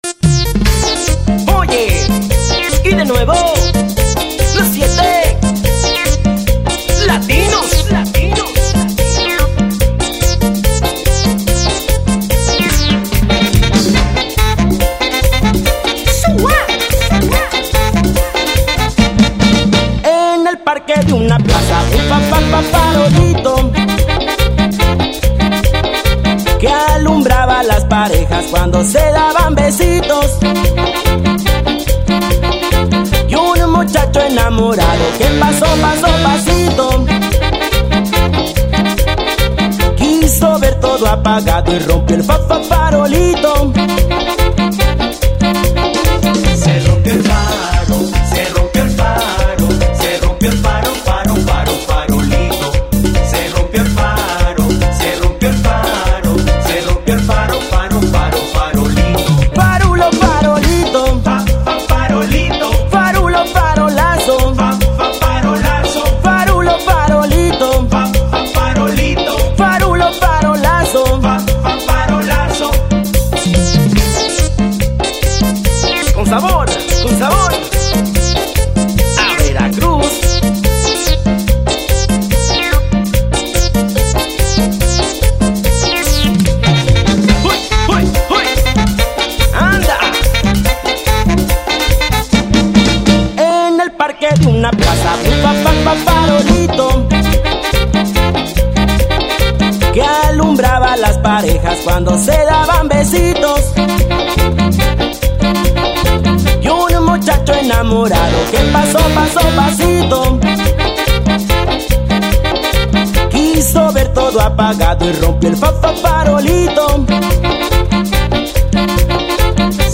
género tropical